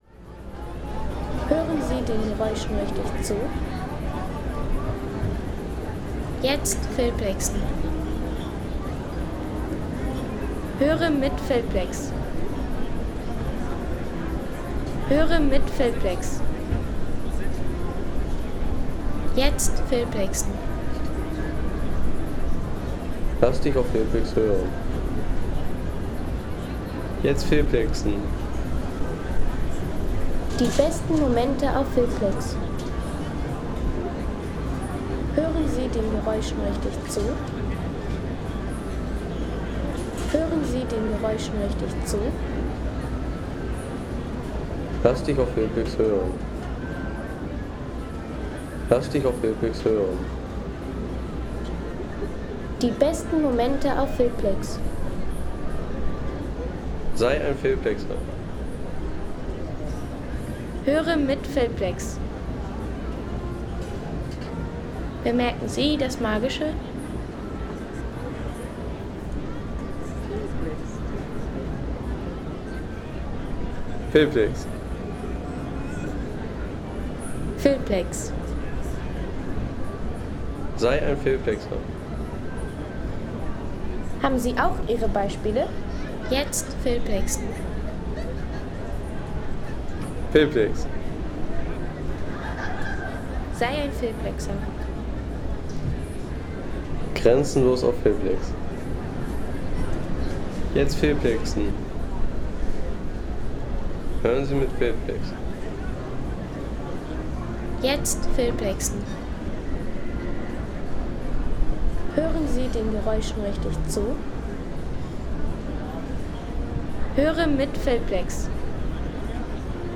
Weihnachtsmarkt in Lübeck
Weihnachtlicher Zauber in Lübeck – Erlebe den Klang des Festes!